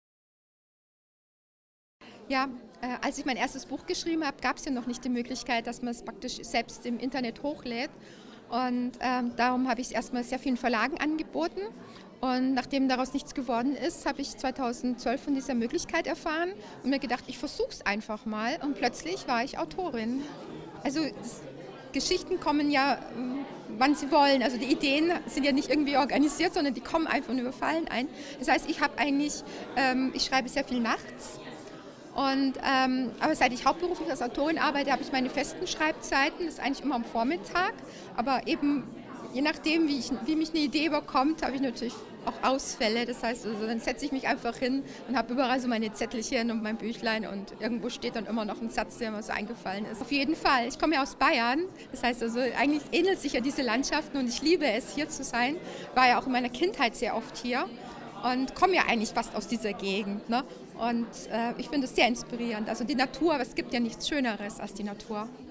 Interviews (ROH) mit.